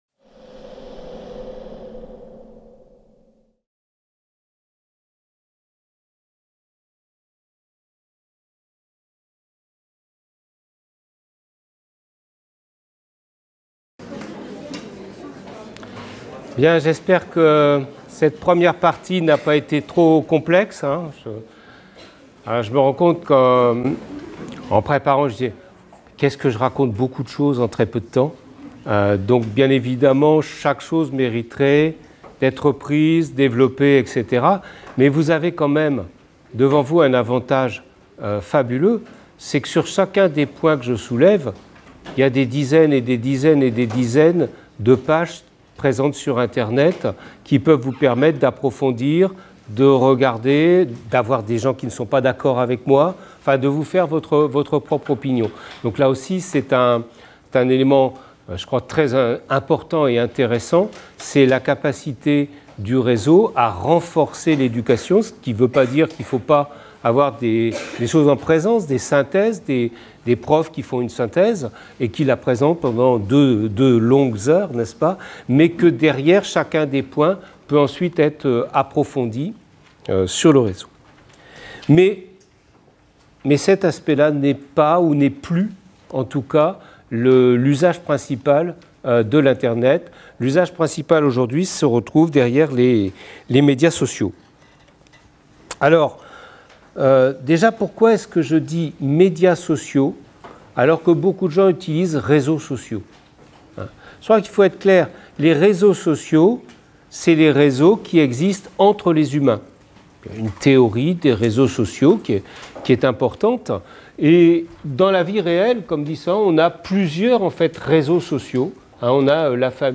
Cours de Culture numérique dans le cadre de la Licence Humanités parcours Humanités numériques.